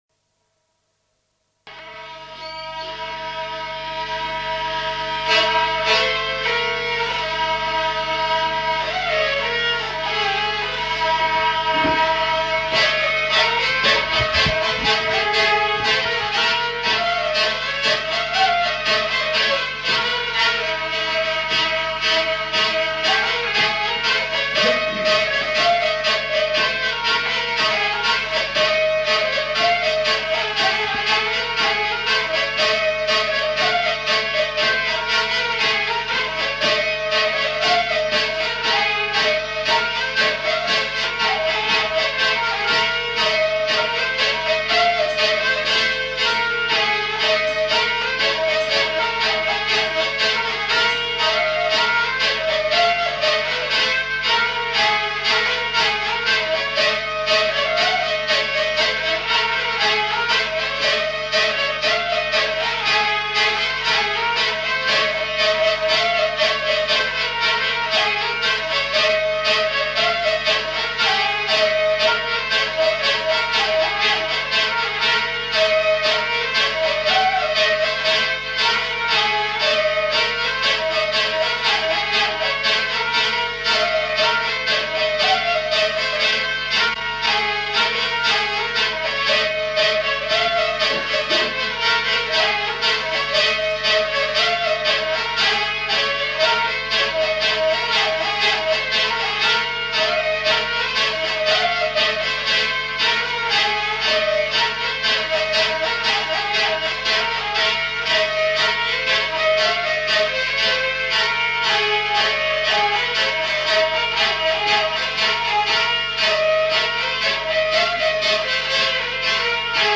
Aire culturelle : Gabardan
Lieu : Mauléon-d'Armagnac
Genre : morceau instrumental
Instrument de musique : vielle à roue
Danse : rondeau
Notes consultables : Enchaînement de deux thèmes.